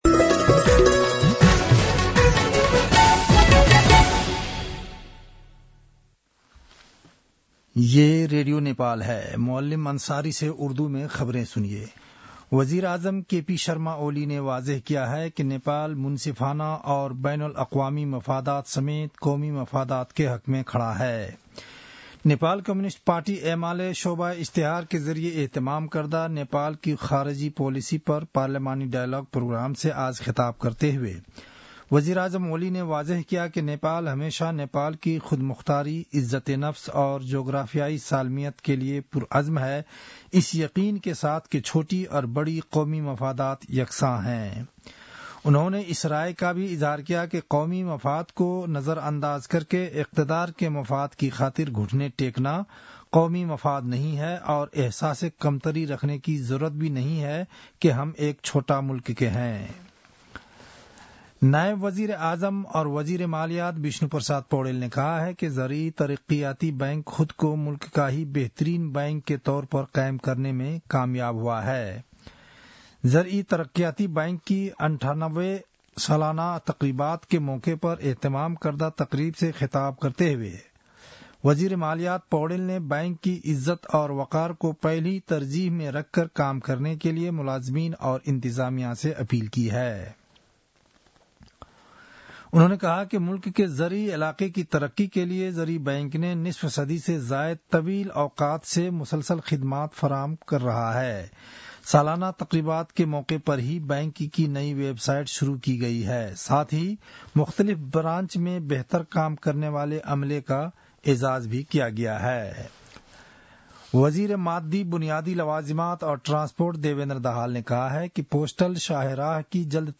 उर्दु भाषामा समाचार : ८ माघ , २०८१